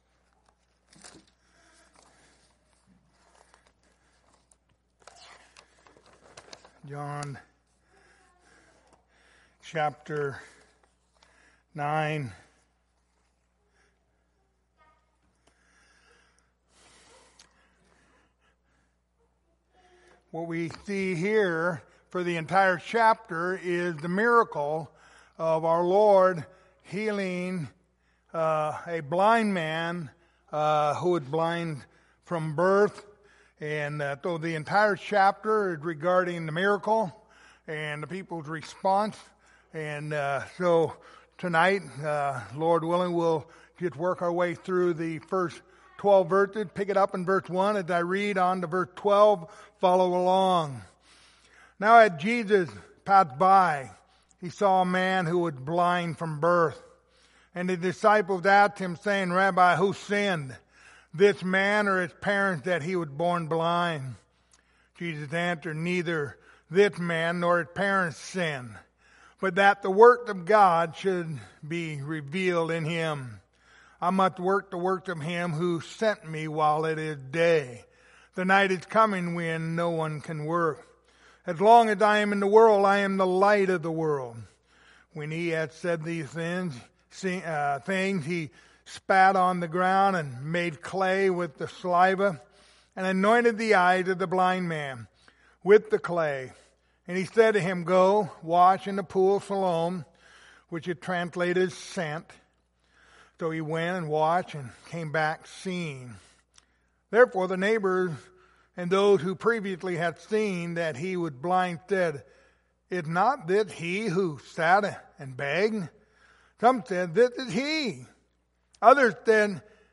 Passage: John 9:1-12 Service Type: Wednesday Evening